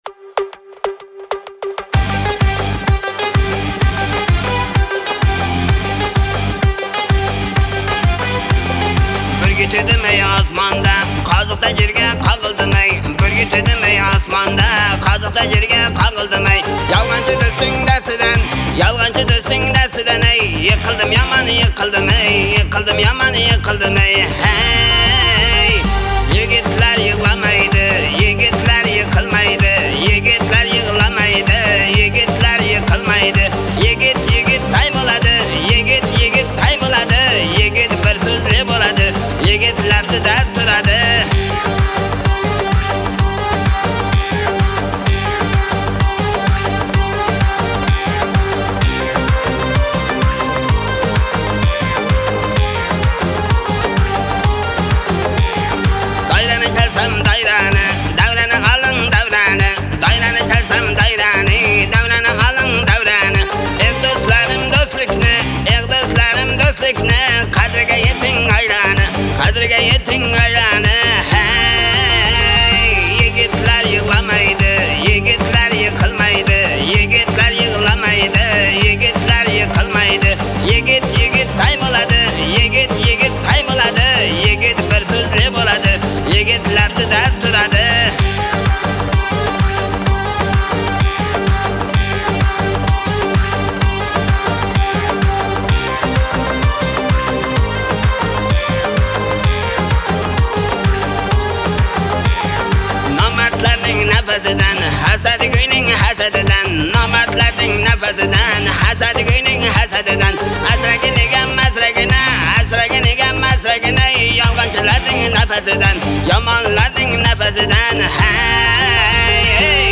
• Жанр: Узбекские песни